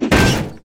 mace.ogg